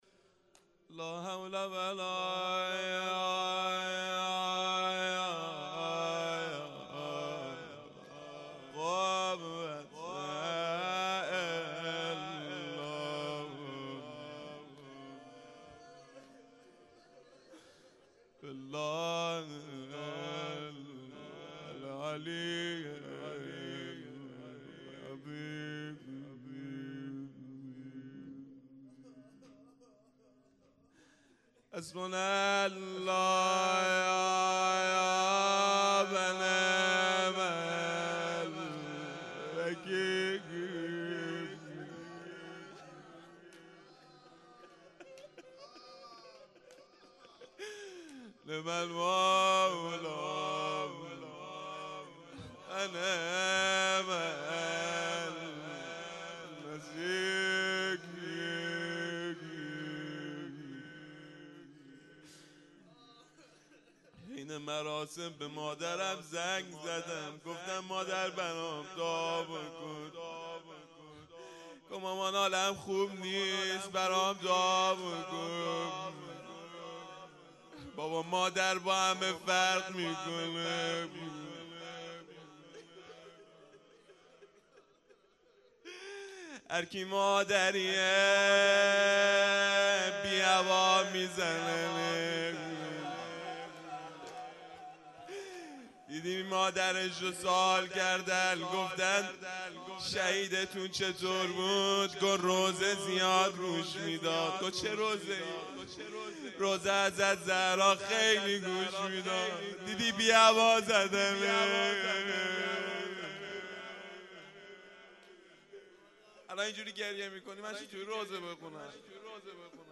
روضه کامل جلسه هفتگی